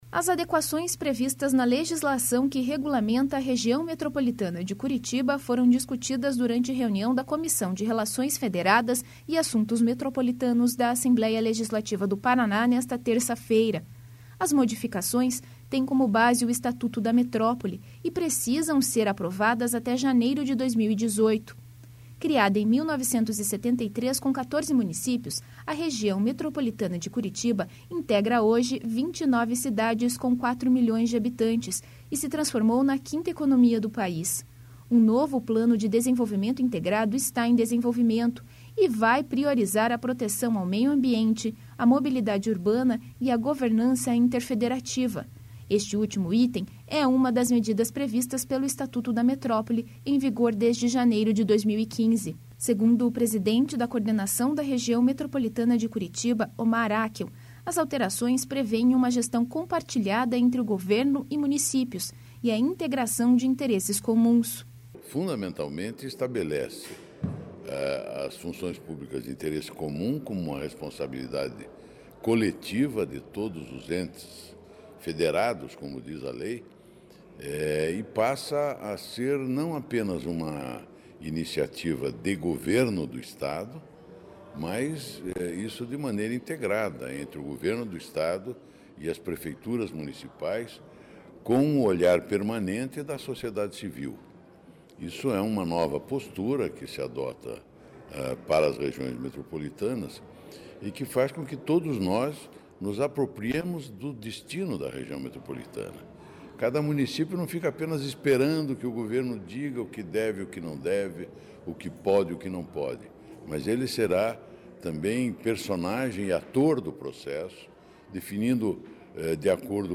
Segundo o presidente da Coordenação da Região Metropolitana de Curitiba, Omar Akel, as alterações preveem uma gestão compartilhada entre governo e municípios e a integração de interesses comuns.